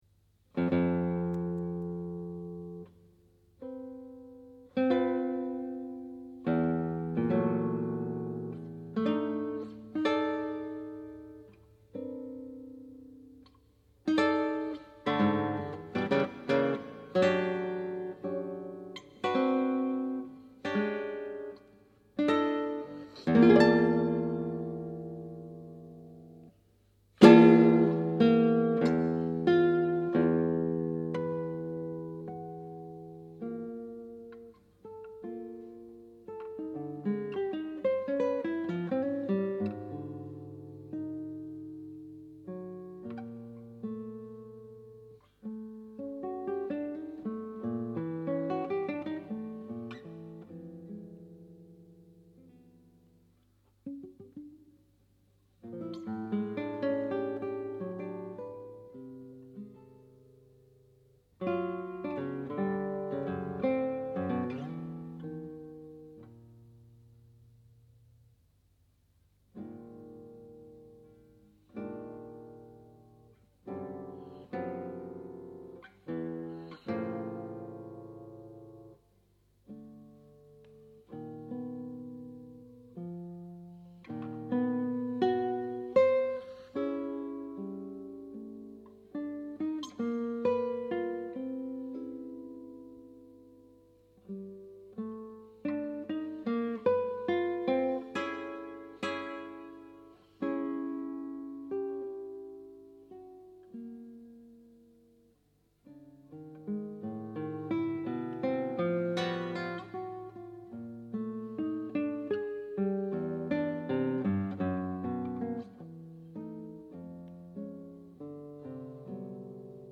Frühe Studioaufnahmen 1980-84  Vol. 2